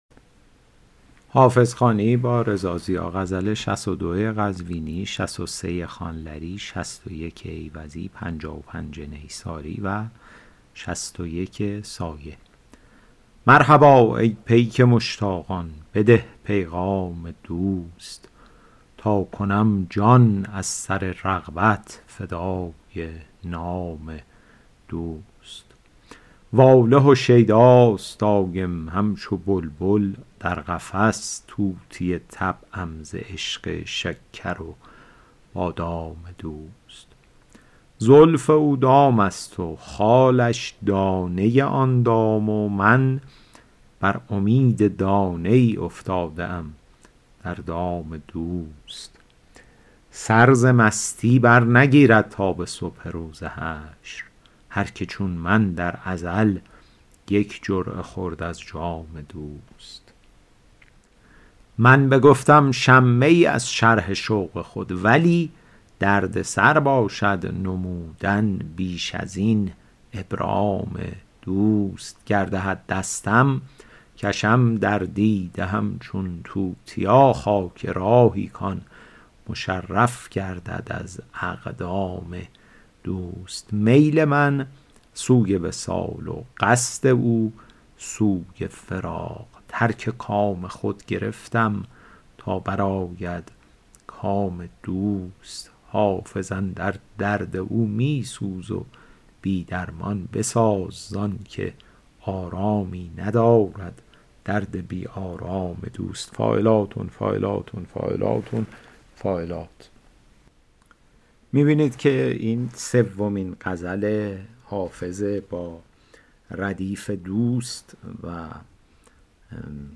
شرح صوتی غزل شمارهٔ ۶۲